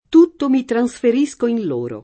trasferire v.; trasferisco [traSfer&Sko], ‑sci — antiq. transferire: transferisco [tranSfer&Sko], ‑sci; es.: tutto mi transferisco in loro [